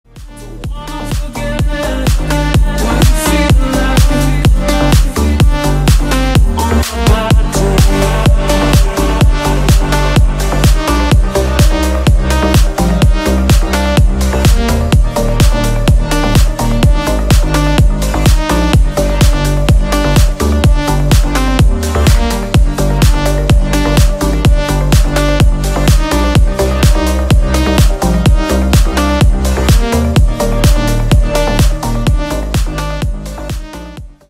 • Качество: 160, Stereo
громкие
deep house
dance
EDM
энергичные
красивый женский голос